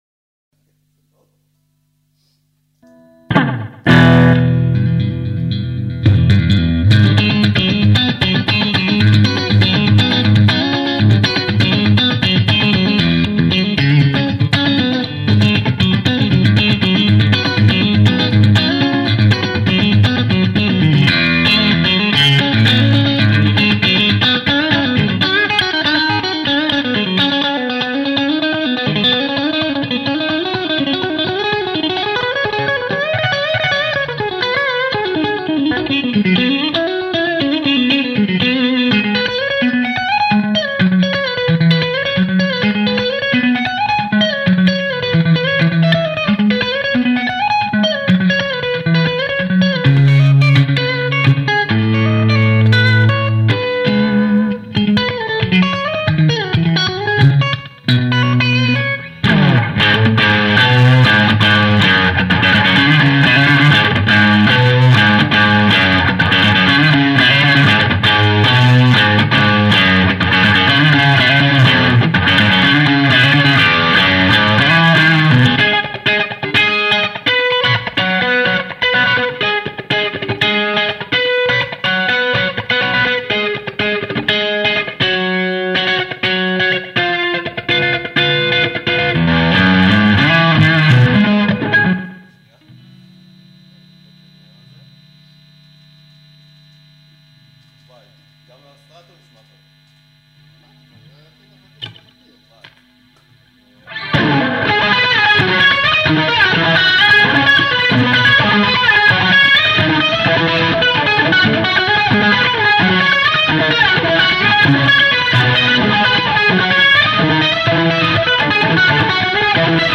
Recorded with a Shure SM57 on a 4×12 Marshall cab and a direct line from the amp’s SM57 mic sim output. Minimal effects—just light reverb, delay, and a touch of wah—to let the amp speak for itself.
Guitar.wav